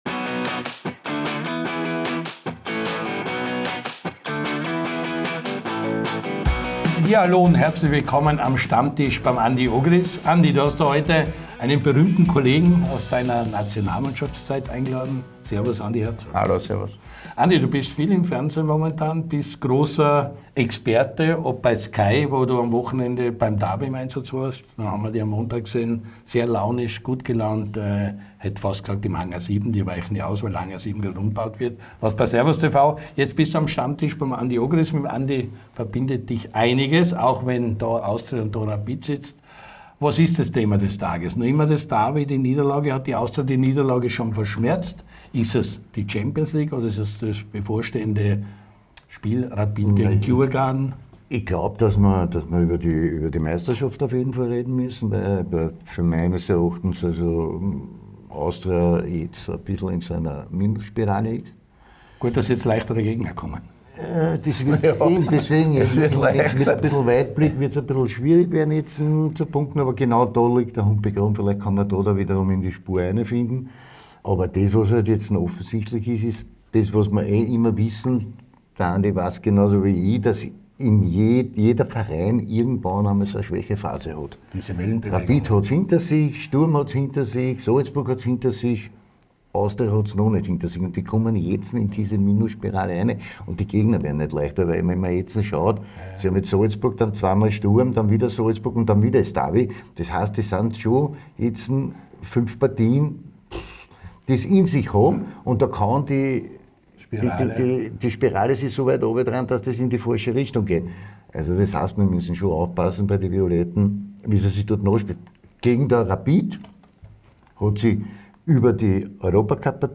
Rückkehr auf die Trainerbank? Nicht ausgeschlossen! Am Stammtisch plaudert und analysiert Andi Herzog mit seinem langjährigen Freund Andy Ogris.